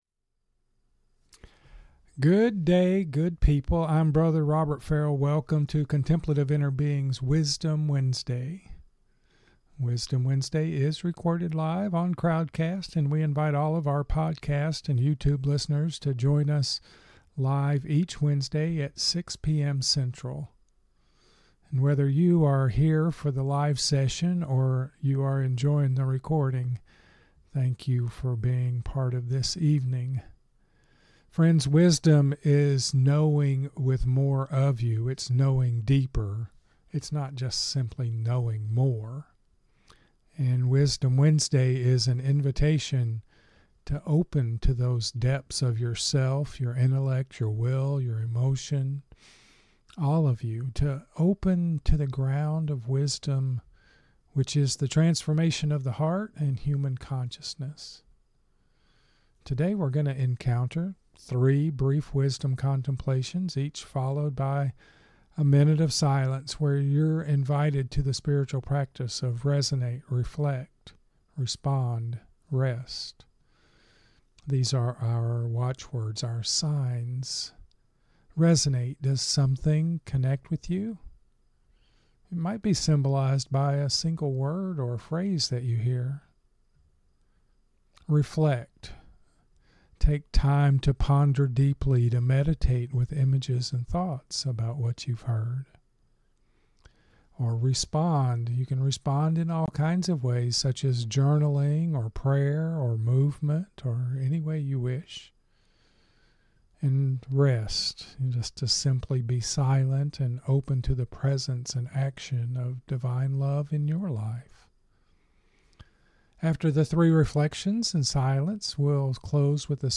Wisdom Wednesday is recorded live on Crowdcast.